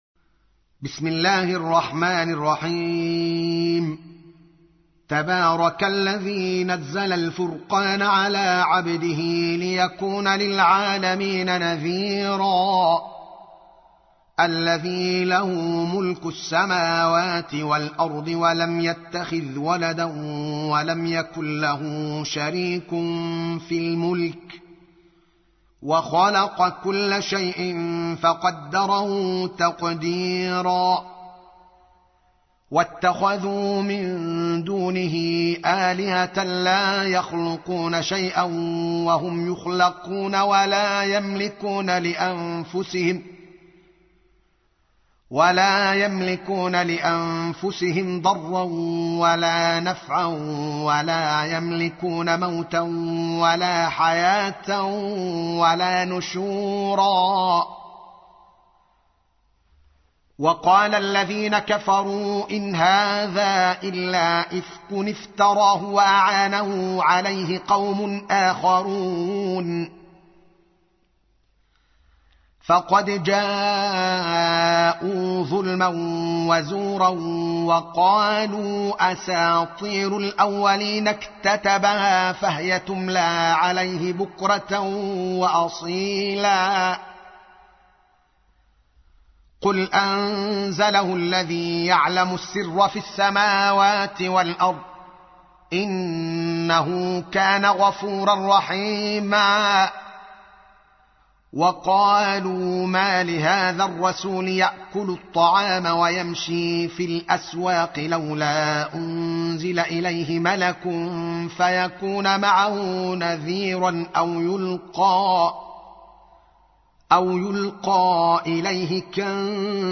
تحميل : 25. سورة الفرقان / القارئ الدوكالي محمد العالم / القرآن الكريم / موقع يا حسين